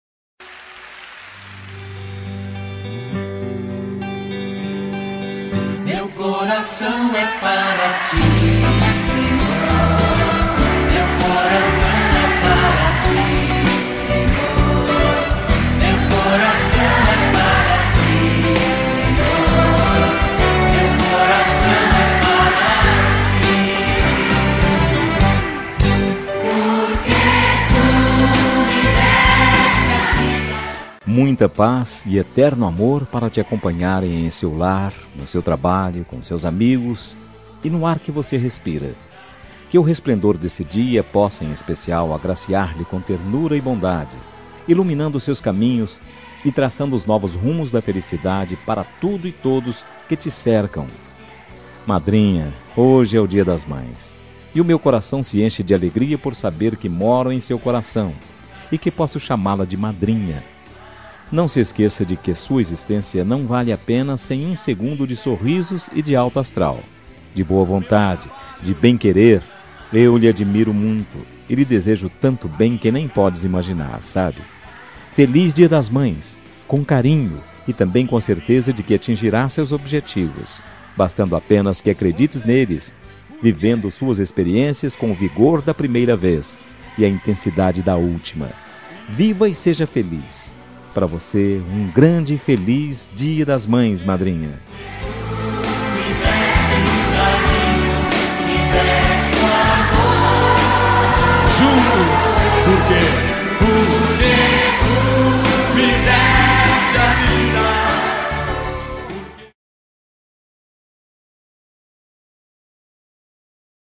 Dia das Mães – Consideração Madrinha – Voz Masculina – Cód: 6812